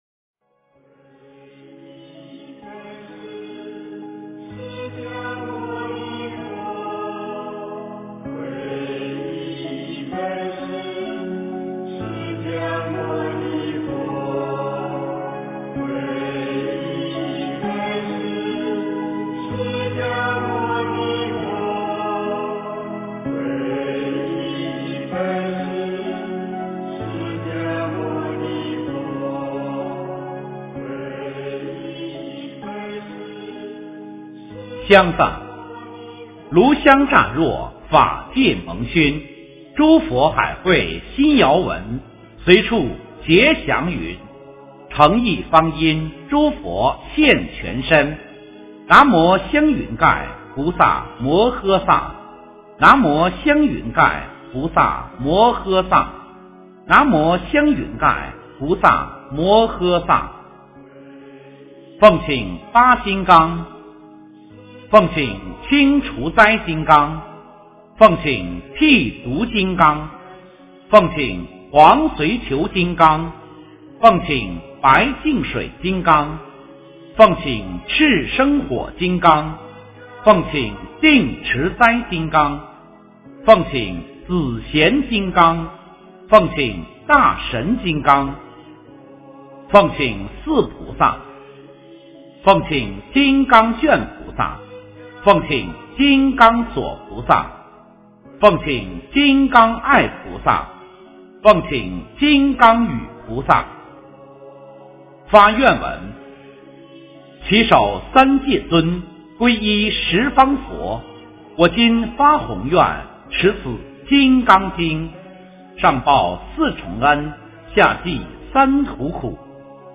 诵经
佛音 诵经 佛教音乐 返回列表 上一篇： 心经-读诵 下一篇： 金刚经的梵音咒语 相关文章 Bhagwan Timrai--琼英卓玛 Bhagwan Timrai--琼英卓玛...